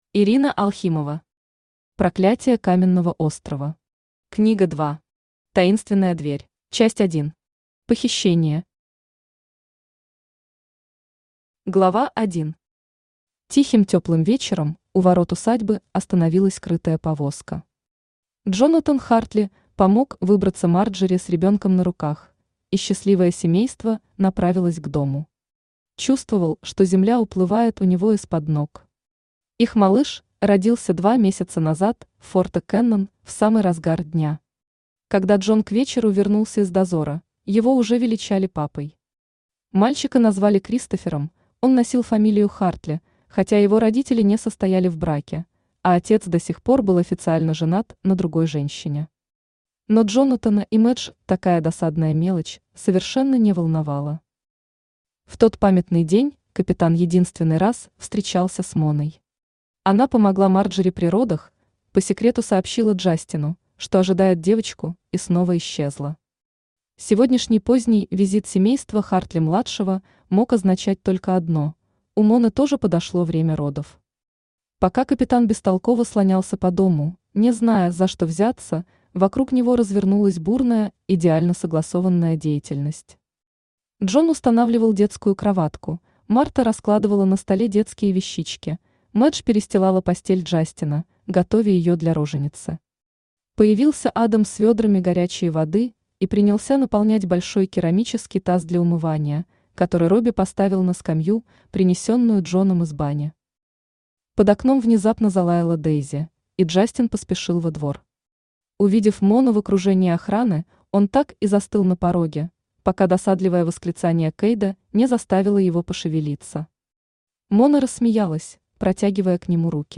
Таинственная дверь Автор Ирина Аркадьевна Алхимова Читает аудиокнигу Авточтец ЛитРес.